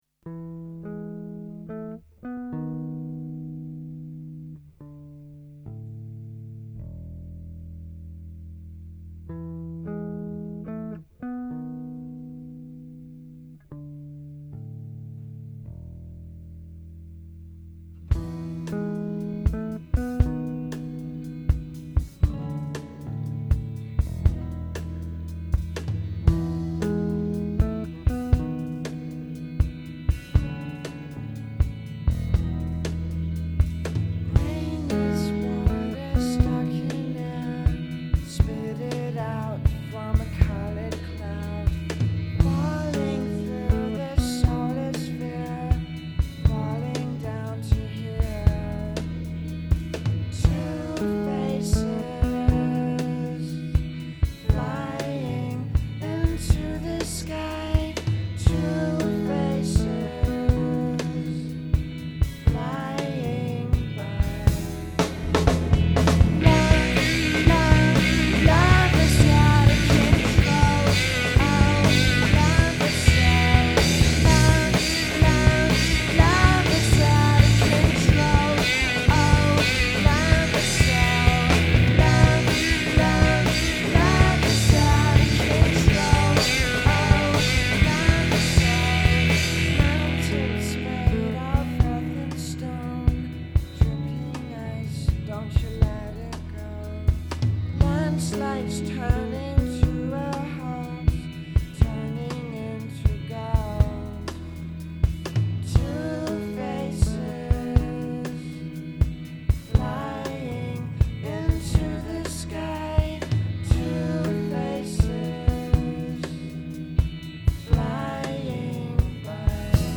vocals, guitar
bass and vocals
drums